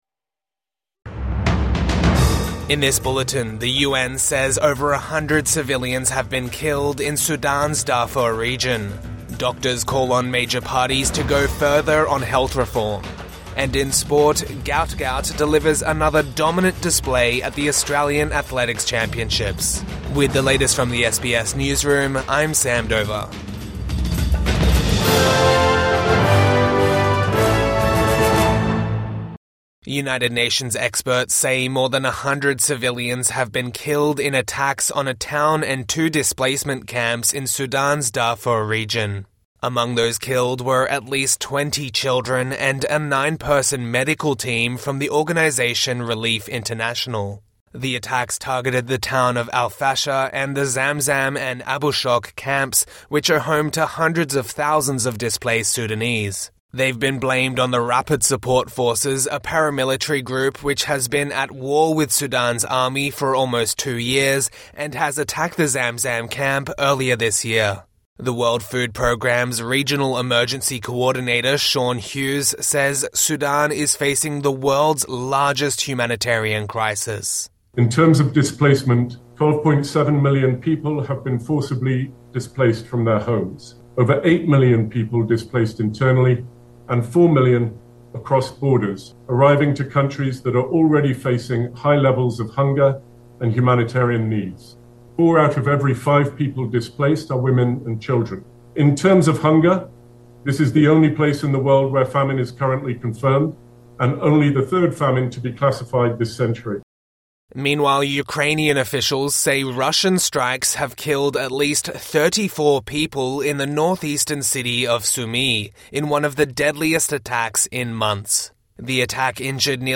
Zelenskyy says deadliest strike in months a Russian 'terror' attack | Morning News Bulletin 14 April 2025